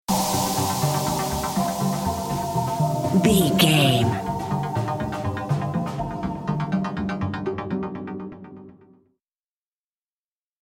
Electronic Disco Music Stinger.
Aeolian/Minor
groovy
uplifting
futuristic
energetic
cheerful/happy
synthesiser
drum machine
house
synth leads
synth bass
upbeat